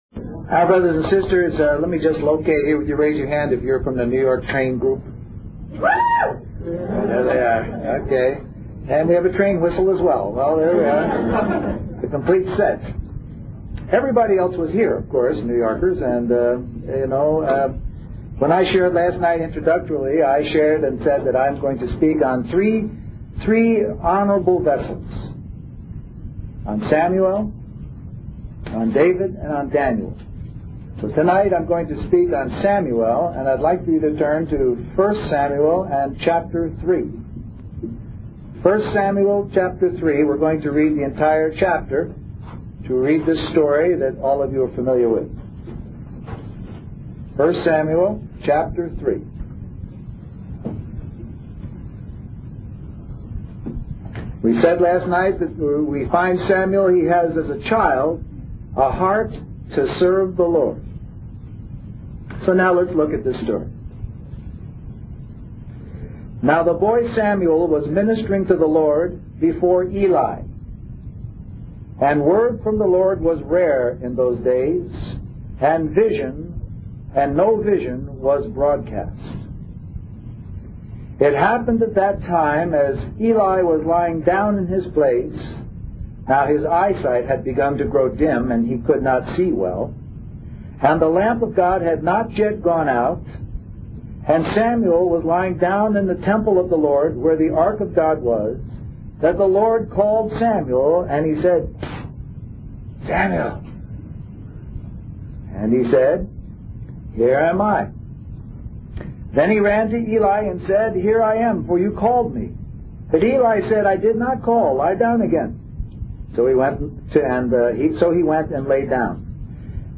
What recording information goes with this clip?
Toronto Summer Youth Conference